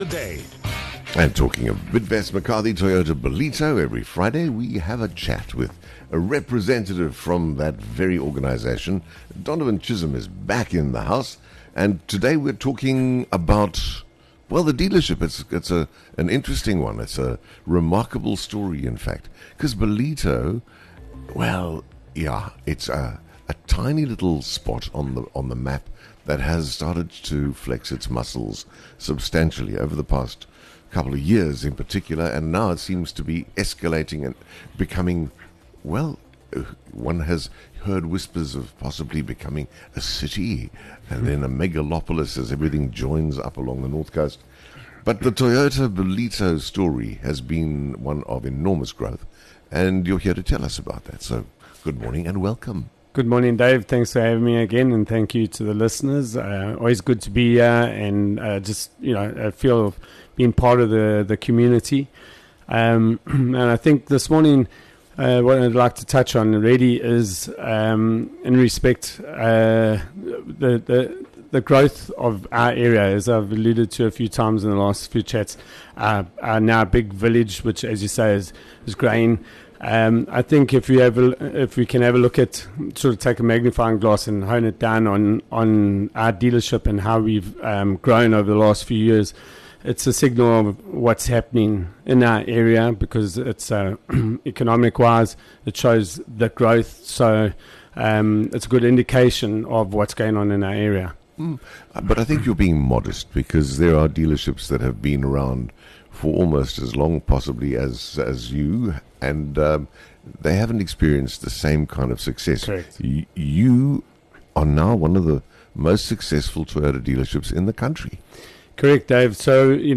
Join us for a featured chat with our valued sponsors of the Morning Show – Bidvest McCarthy Toyota Ballito, driving excellence every morning.